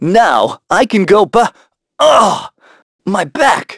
Evan-Vox_Victory_a.wav